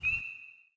frog6.wav